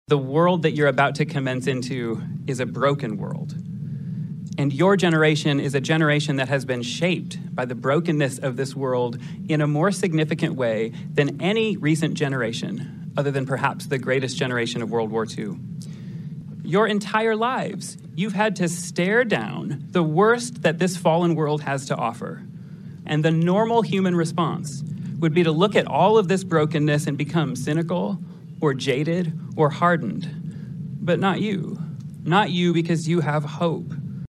UPDATE: Hope College Graduation Ceremony Held on Sunday